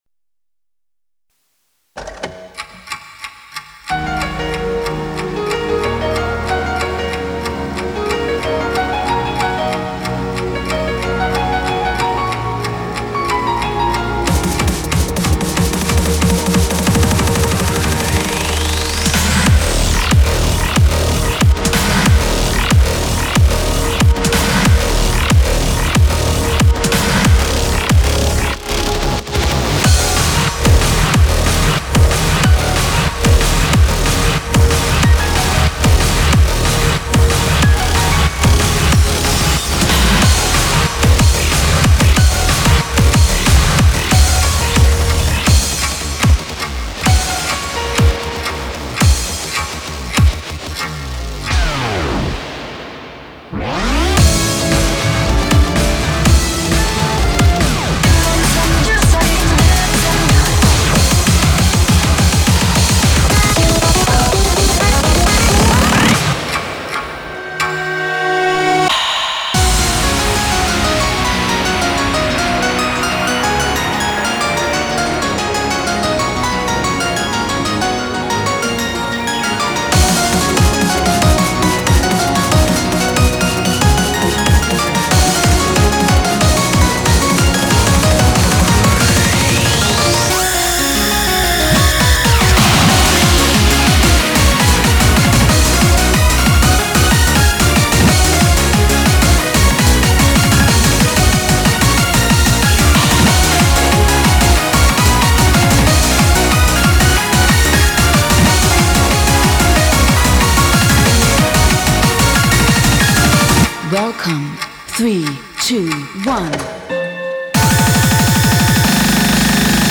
BPM47-370
Comments[TRANCE CORE]